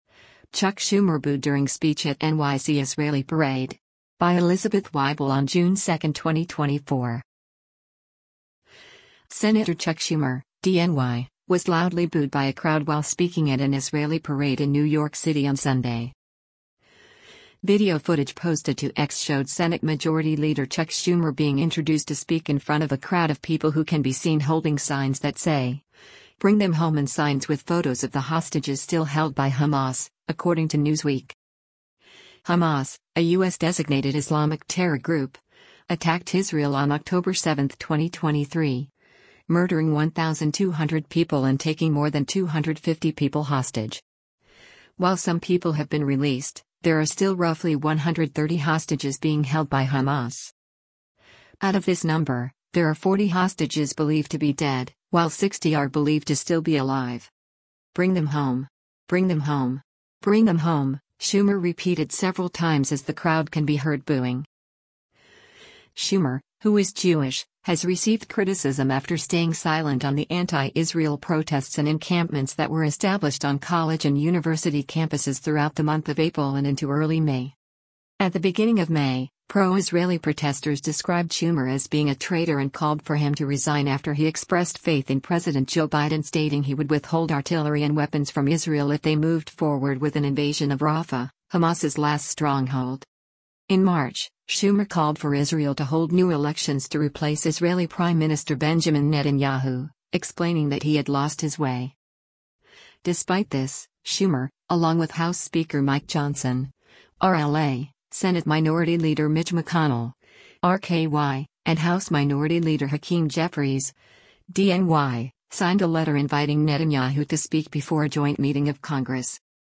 Sen. Chuck Schumer (D-NY) was loudly booed by a crowd while speaking at an Israeli parade in New York City on Sunday.
“Bring them home. Bring them home. Bring them home,” Schumer repeated several times as the crowd can be heard booing.